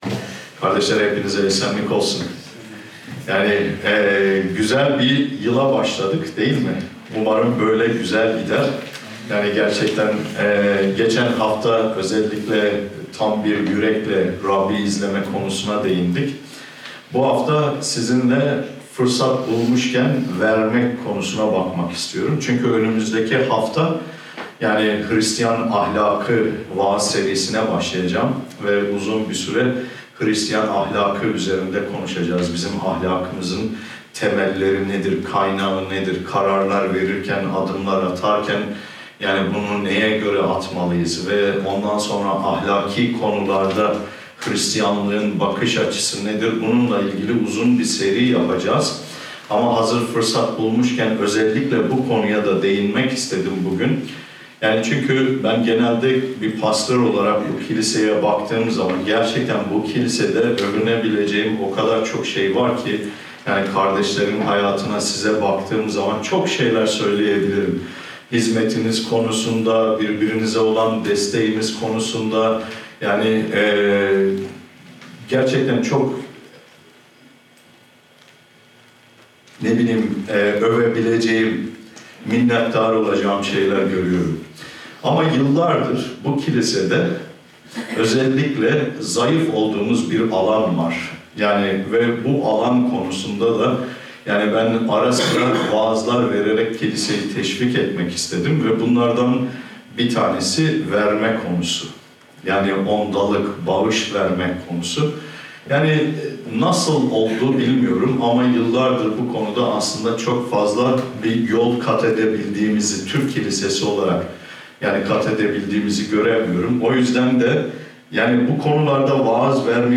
HRİSTİYAN AHLAKI VAAZ SERİSİ NO:1